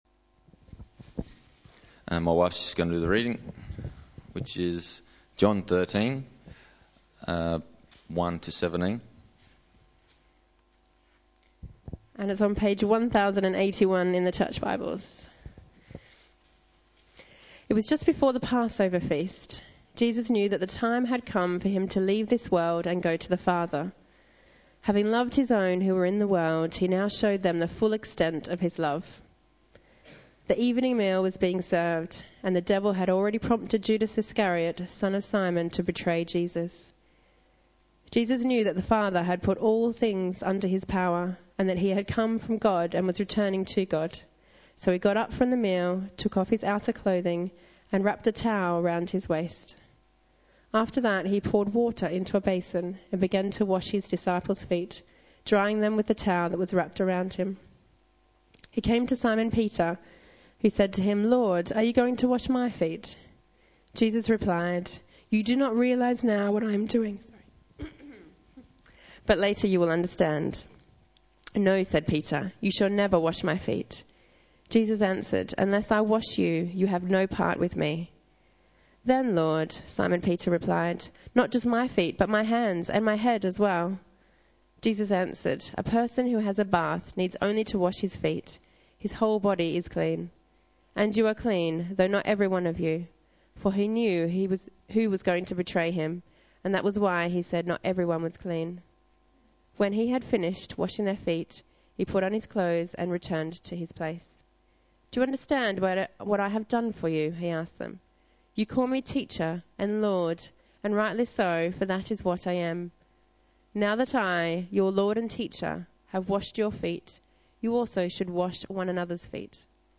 Media for Sunday Service on Sun 25th Mar 2012 11:00
Series: 40 Days of Purpose Theme: Ministry Sermon